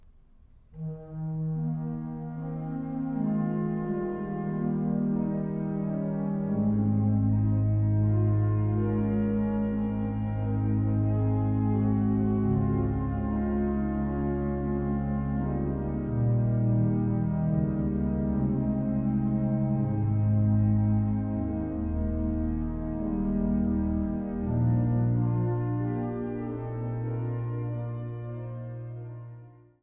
Führer-Skrabl-Orgel in der Evangelischen Kirche Saarlouis